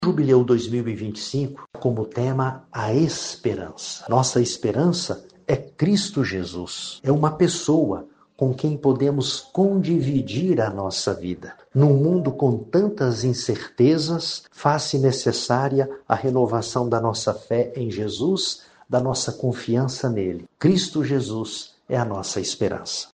O bispo também destaca a escolha do tema para este tempo especial na Igreja que é "Peregrinos da Esperança”: